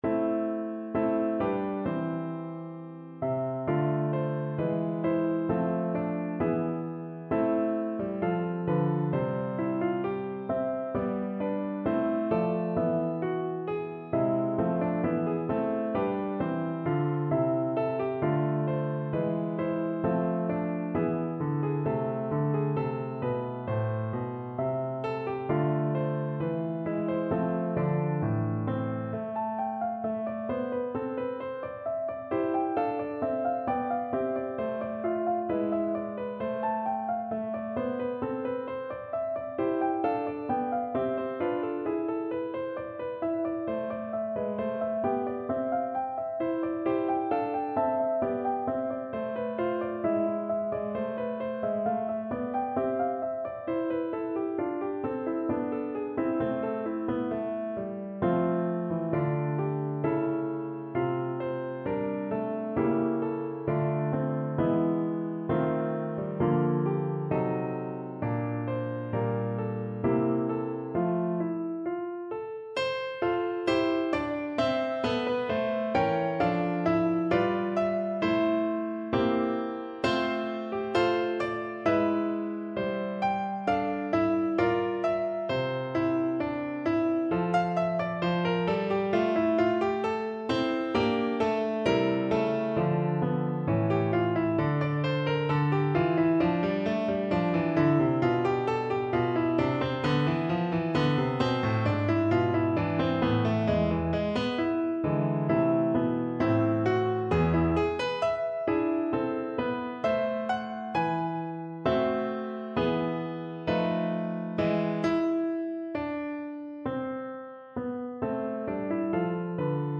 No parts available for this pieces as it is for solo piano.
A minor (Sounding Pitch) (View more A minor Music for Piano )
Moderato =66
2/2 (View more 2/2 Music)
Piano  (View more Intermediate Piano Music)
Classical (View more Classical Piano Music)
chauvet_offertoire_noel_PNO.mp3